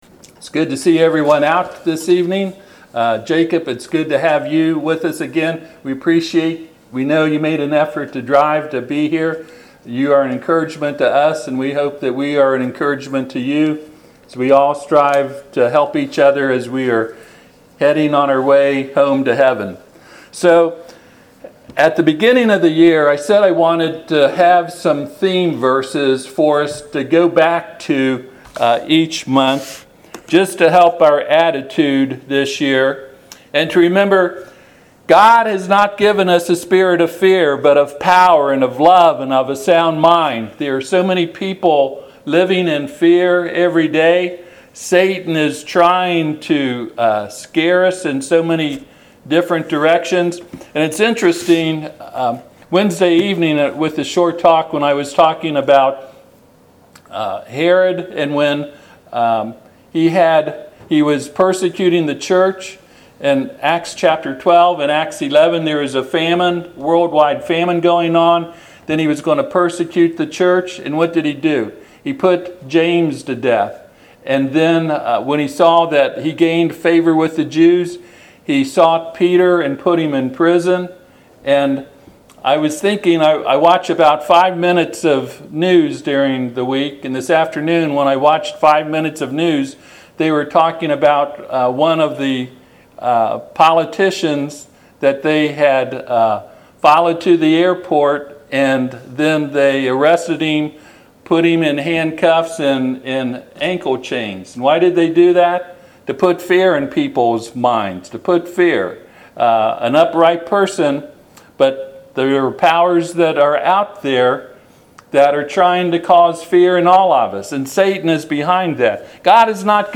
Jeremiah 20:9 Service Type: Sunday PM Jeremiah 20:9 Then I said